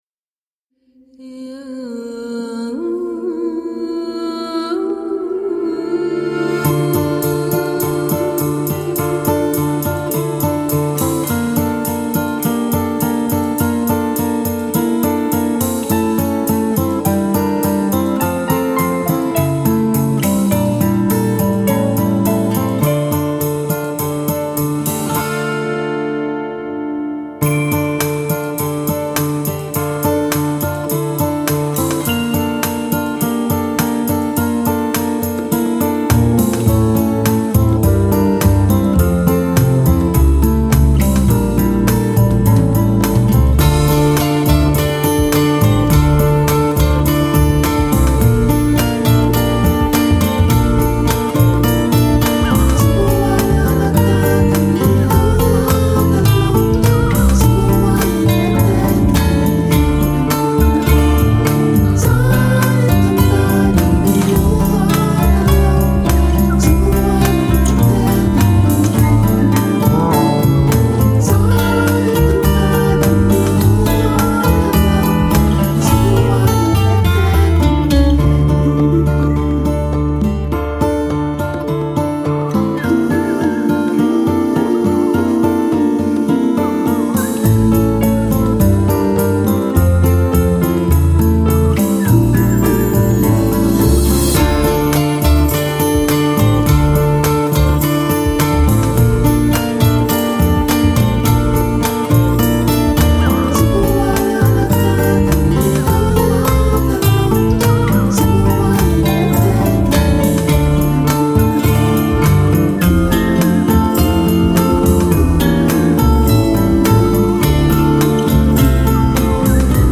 The winner was chosen due to the constant quality of  his composition from beginning to end, the nice transitions and flow to overall piece and his great use of acoustic bass to supplement the tracks provided.